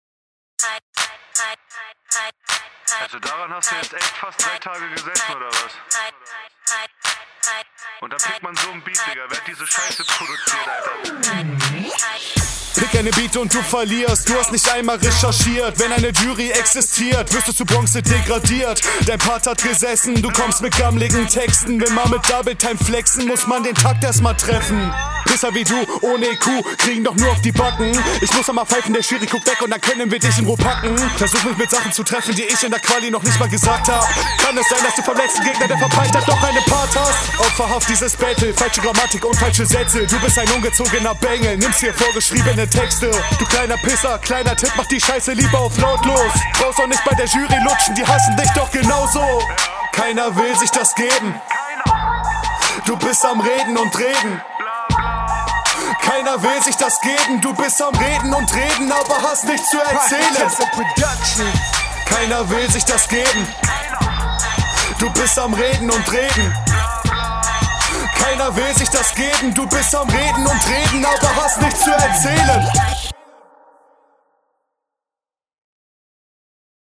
Also dieses Hook Ding am Ende kommt leider echt nicht geil.